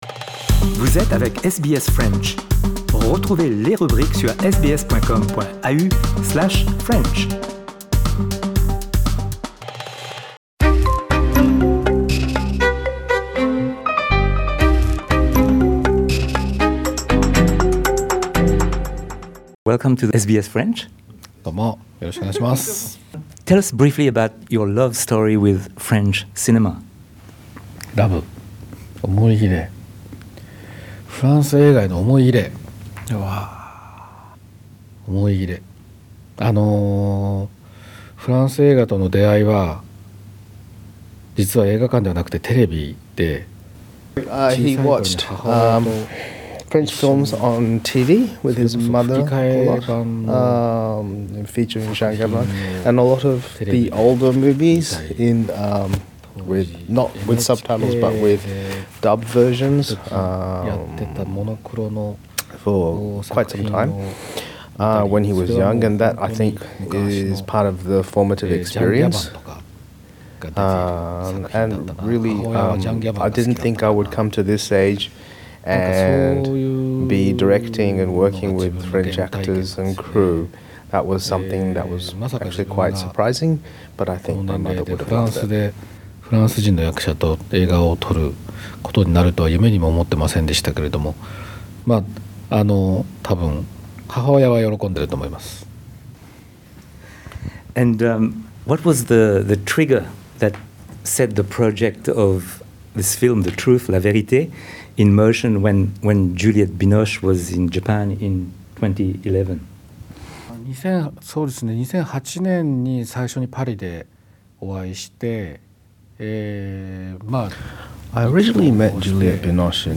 Rencontre avec le réalisateur japonais Kore-eda Hirokazu qui a tourné The Truth ( La Vérité ) avec Catherine Deneuve et Juliette Binoche. Le film sort sur le grand écran en Australie le jour de Boxing Day, le 26 décembre.